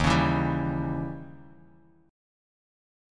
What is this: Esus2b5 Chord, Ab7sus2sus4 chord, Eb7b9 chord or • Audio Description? Eb7b9 chord